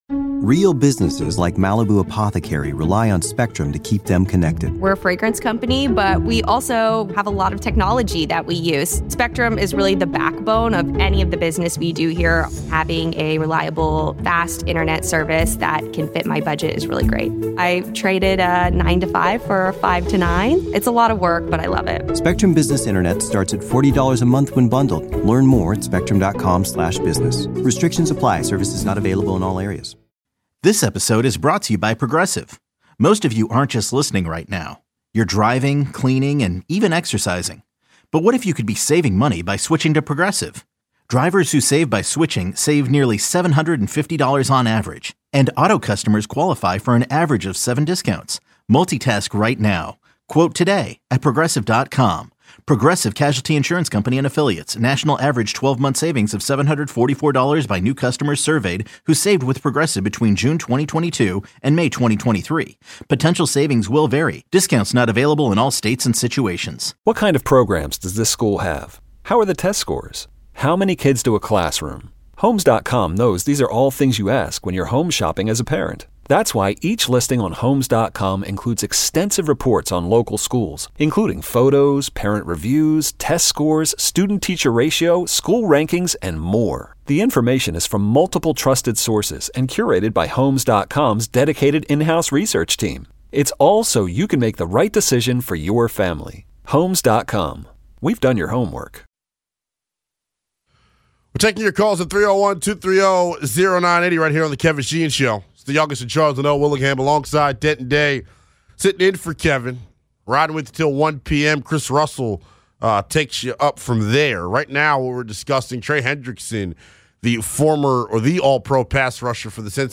Hour 2, Callers give their thoughts on if the Commanders should trade for Trey Hendrickson.